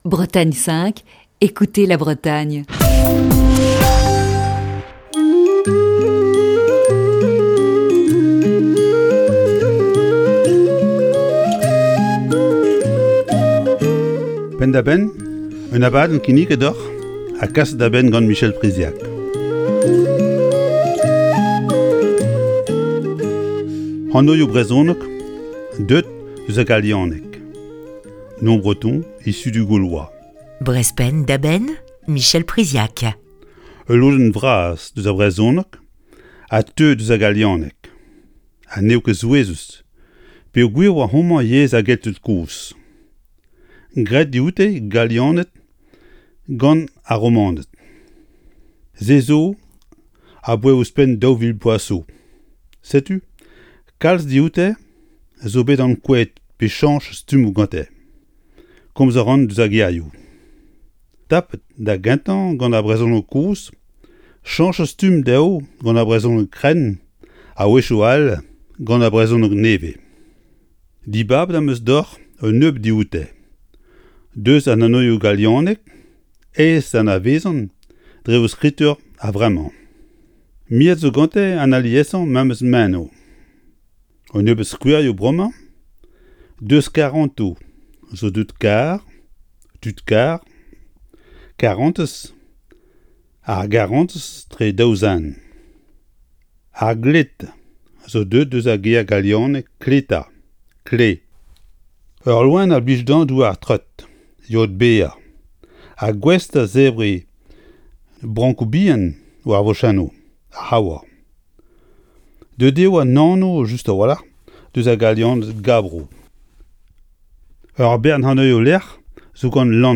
Chronique du 11 juin 2020.